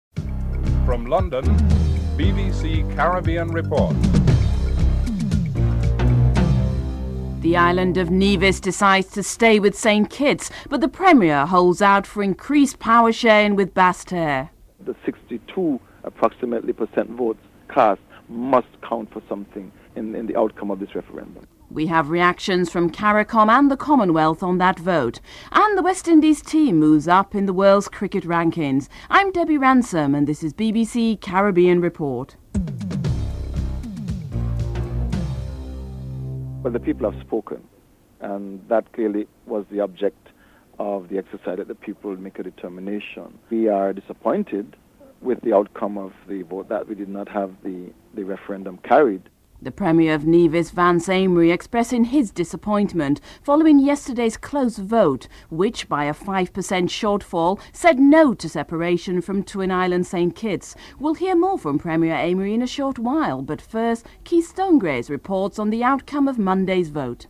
1. Headlines (00:00-00:34)
Premier Vance Amory, Dr Kenny Anthony, Caricom and Commonwealth Secretary General Chief Emeka Anyaoku are interviewed.